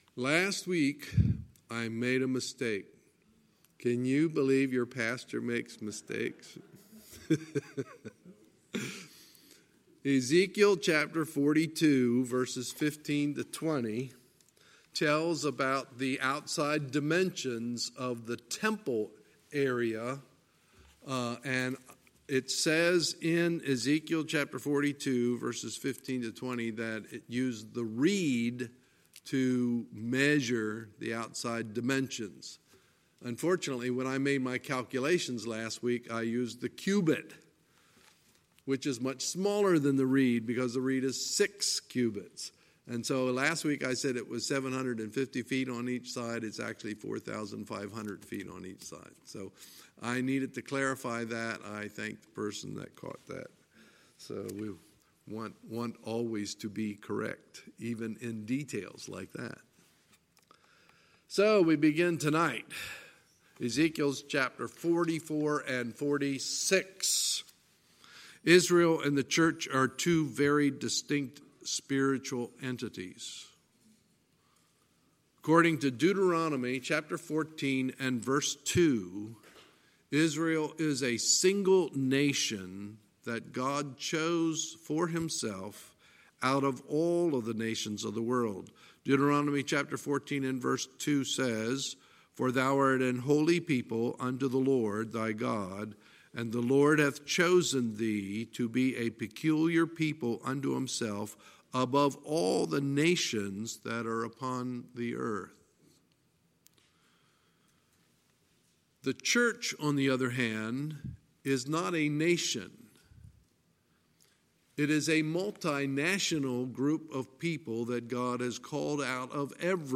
Sunday, September 8, 2019 – Sunday Evening Service
Sermons